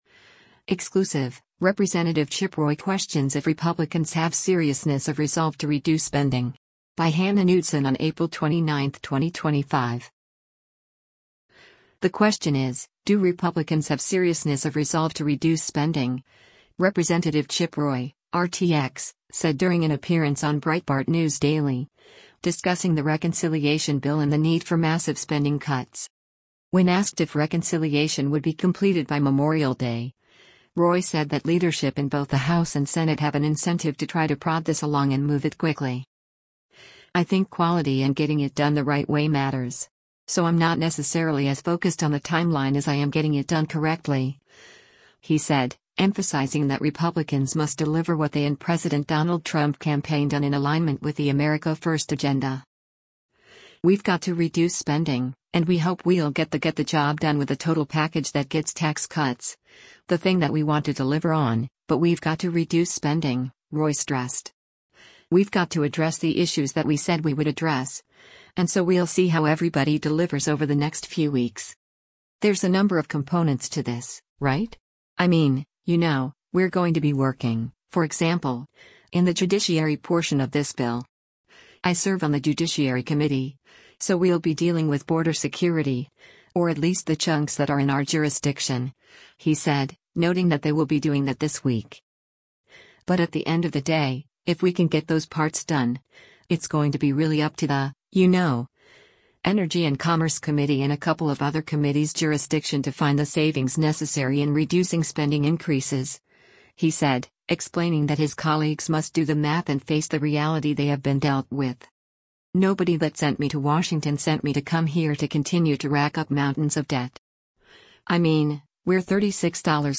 The question is, do Republicans have “seriousness of resolve to reduce spending,” Rep. Chip Roy (R-TX) said during an appearance on Breitbart News Daily, discussing the reconciliation bill and the need for massive spending cuts.
Breitbart News Daily airs on SiriusXM Patriot 125 from 6:00 a.m. to 9:00 a.m. Eastern.